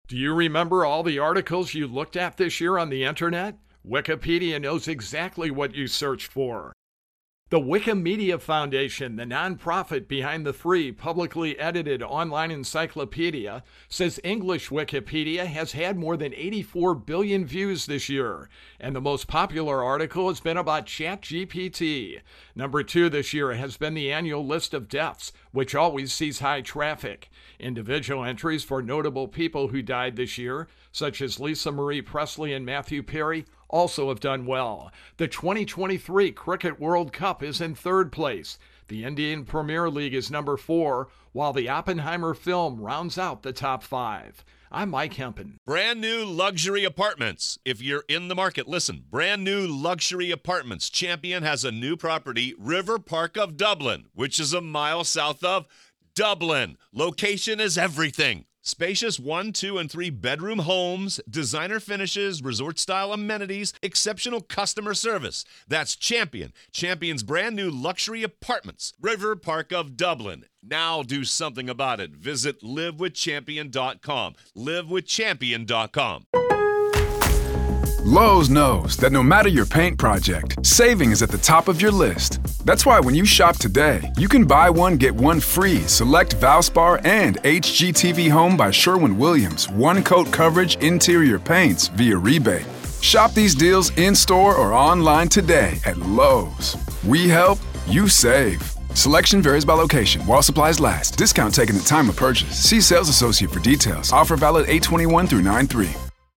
AP correspodnent